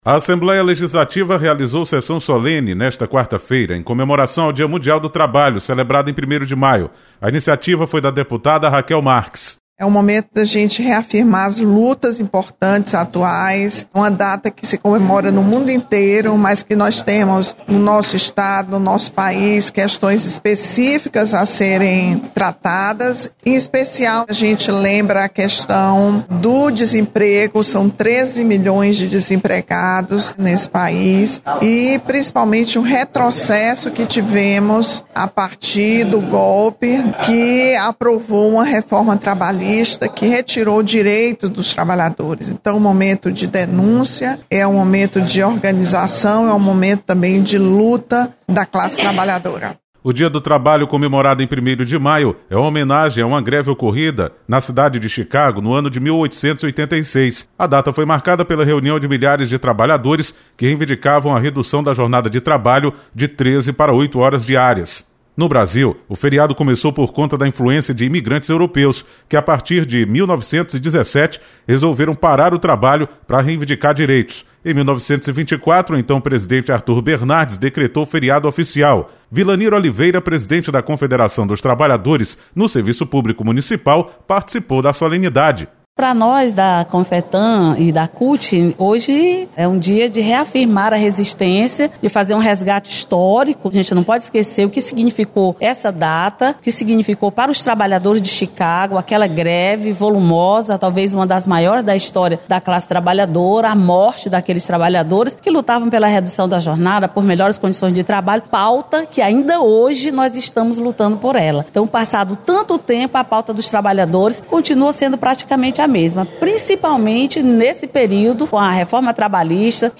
Assembleia Legislativa  realiza solenidade em comemoração ao Dia do Trabalho. Repórter